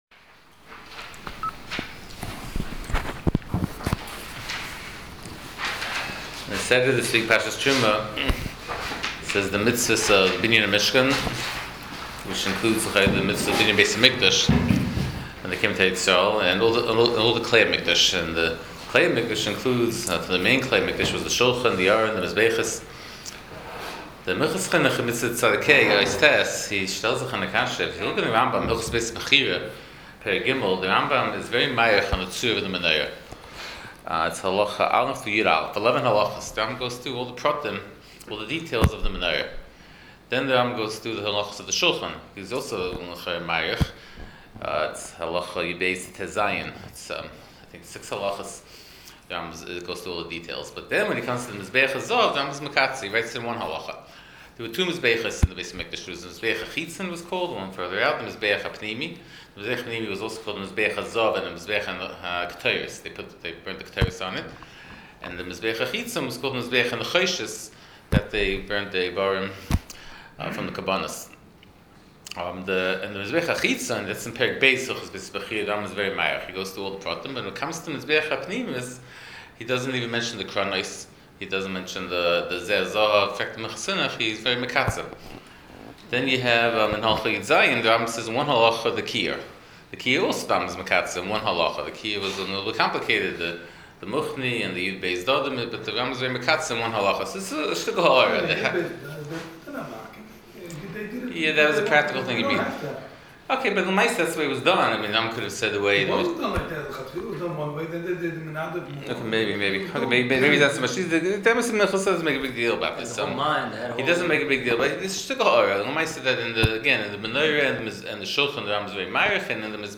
Shiur provided courtesy of Madison Art Shop.